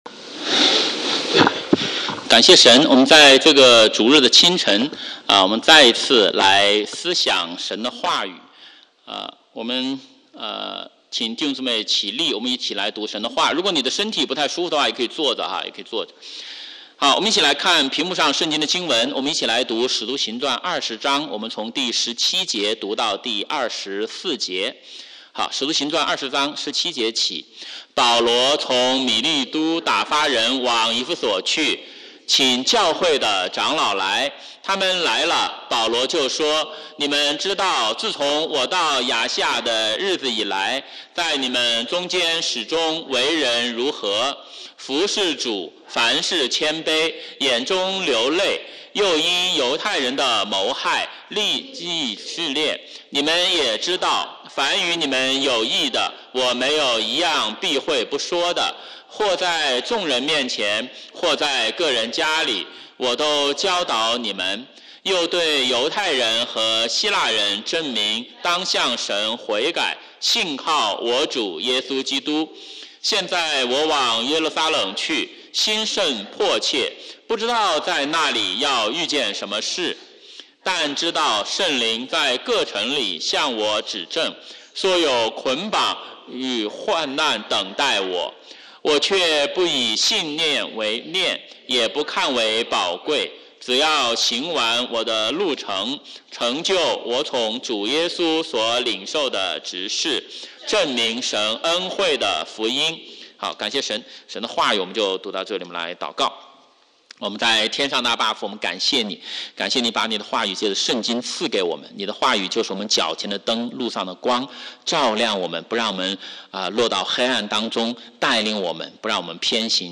華語主日崇拜講道錄音